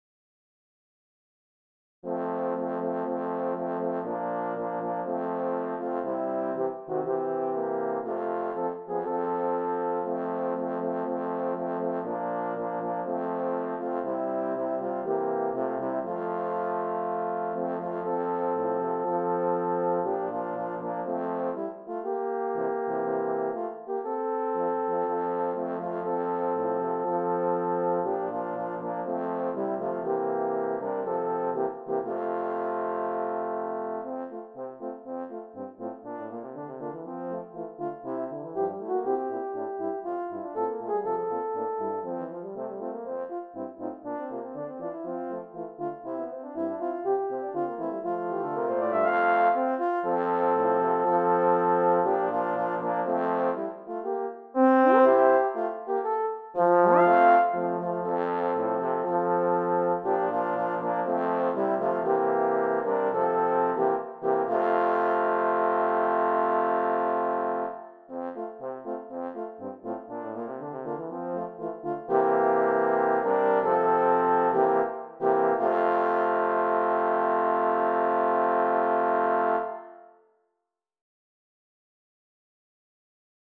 This is a public domain hymn composed by Lewis E. Jones. I have arranged it for HORN QUARTET using the FINALE music software, and the audio is actually produced by the FINALE program.